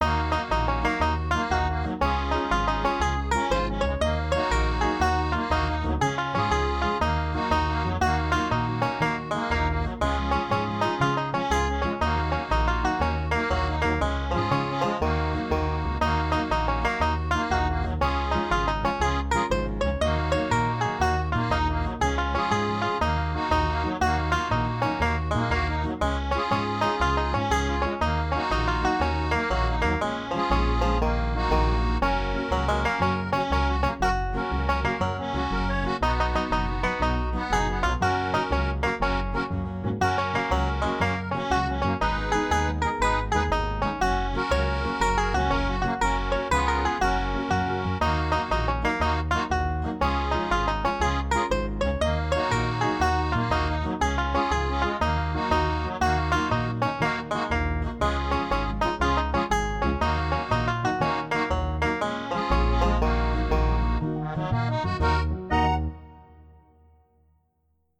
バンジョー、アコーディオン、アコースティックギター、ピアノ、アコースティックベース ※ケルト楽器
BGM